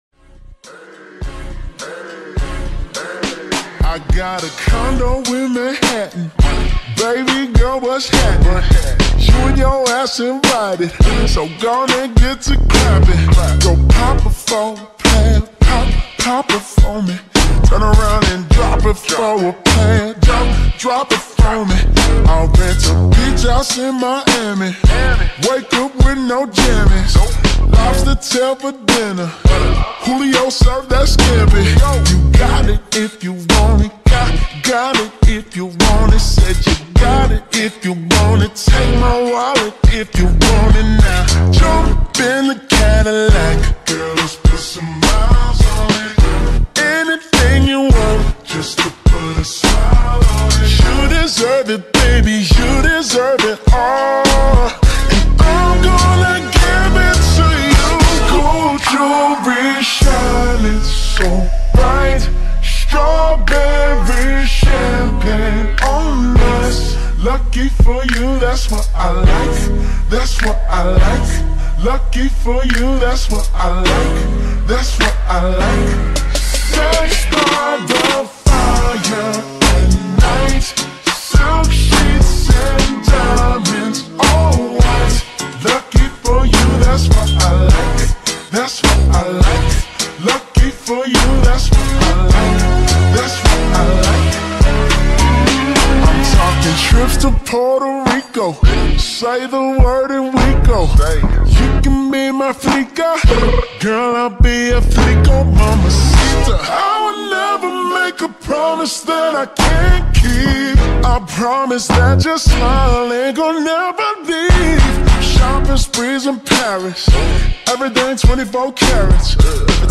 • Качество: 320 kbps, Stereo
TikTok remix